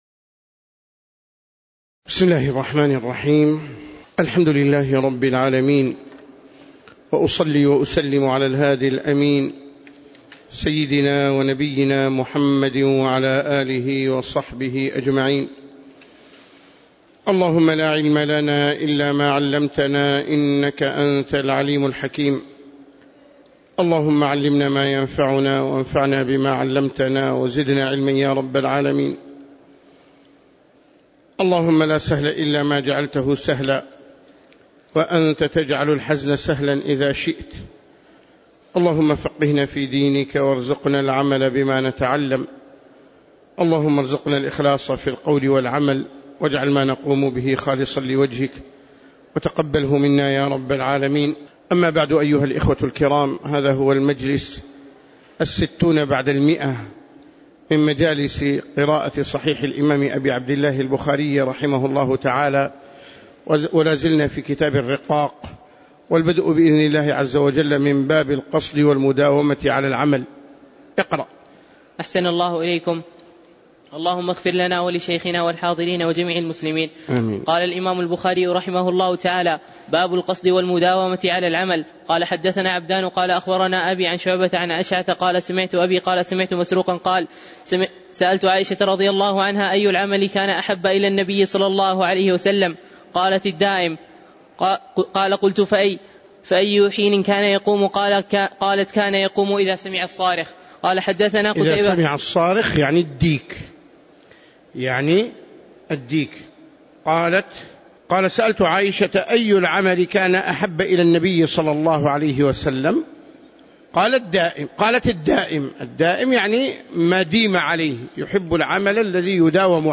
تاريخ النشر ٤ صفر ١٤٣٩ هـ المكان: المسجد النبوي الشيخ